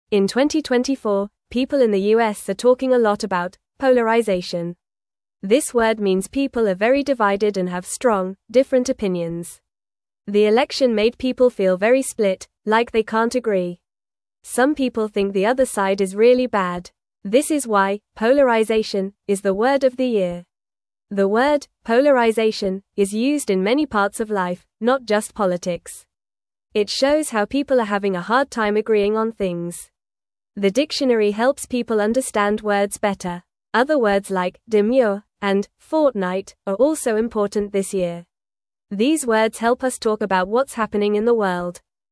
Fast
English-Newsroom-Beginner-FAST-Reading-People-Are-Divided-The-Word-of-the-Year.mp3